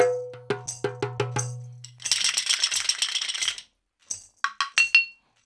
88-PERC7.wav